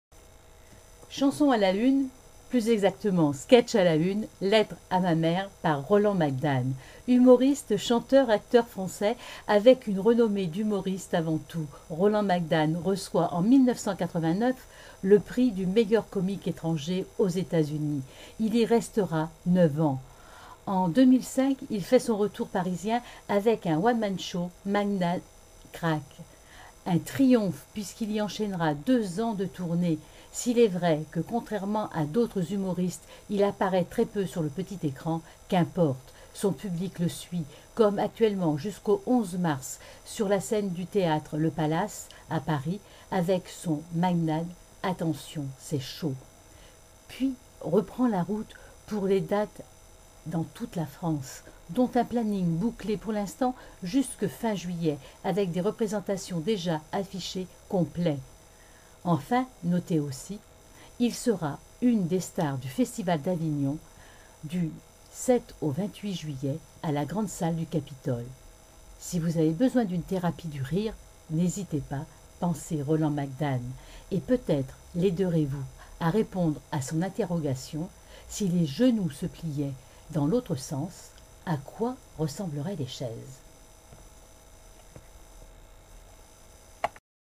Lettre à ma mère. Sketch: Roland Magdane. Interprète: Roland Magdane. (2011)